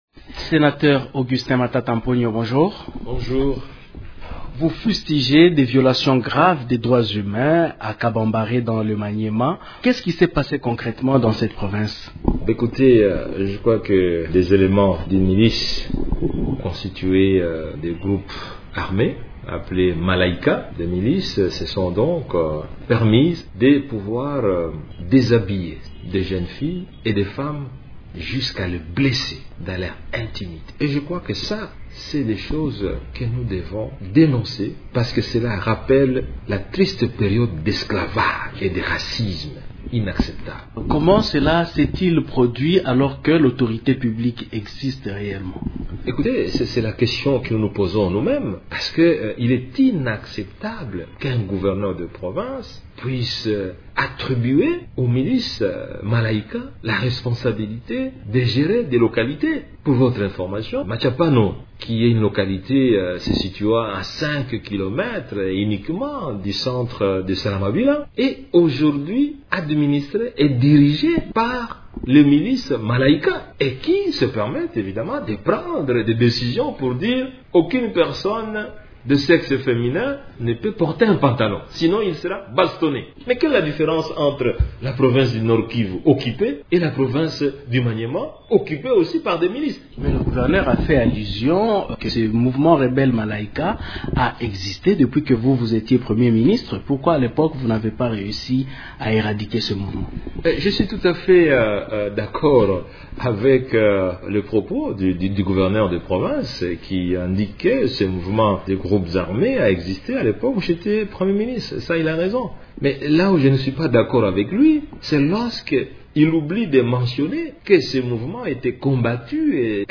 Augustin Matata Ponyo s’entretient